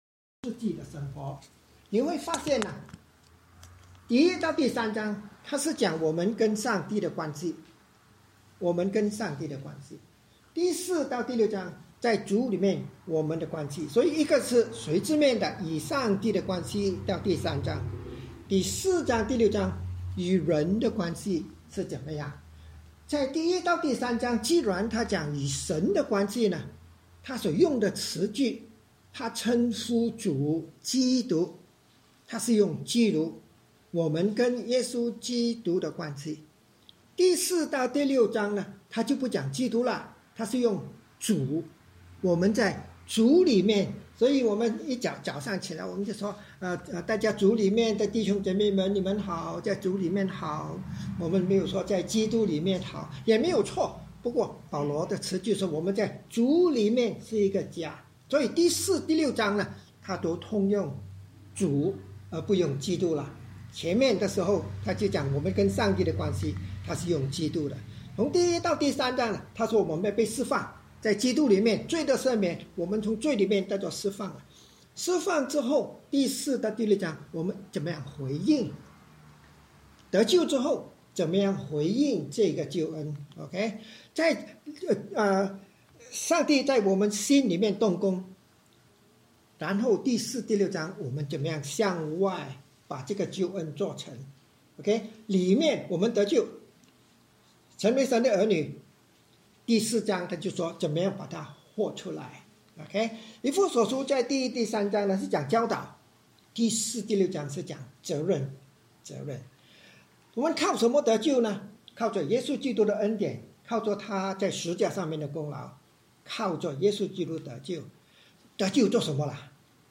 以弗所书1：1-6 Service Type: 主日崇拜 通过以弗所书的概览让我们认识圣父、圣子、圣灵为我们所成就的是何等大的救恩，使我们藉着基督在教会赐下丰盛福气，甘心在教会服事并向身边的人活出基督徒生命的见证，使人看见就称赞我们所信的是真神。